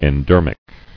[en·der·mic]